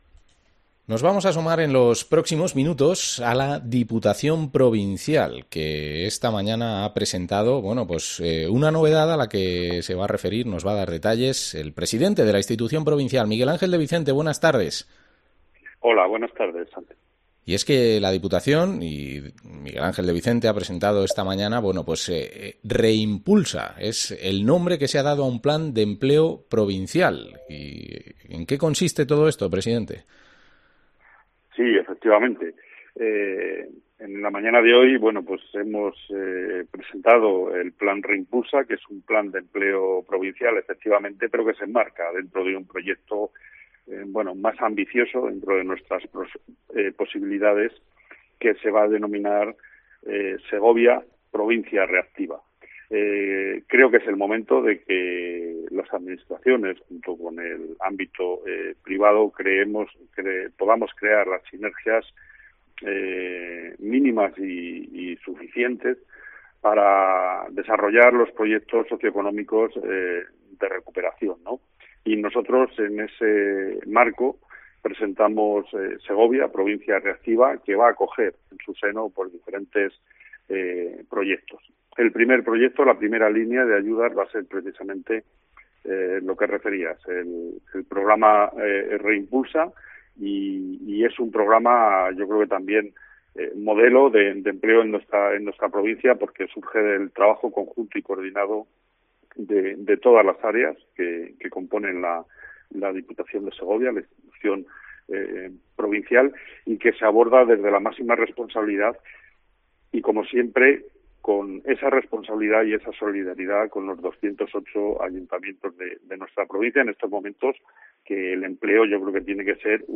Entrevista al presidente de la Diputación de Segovia, Miguel Ángel de Vicente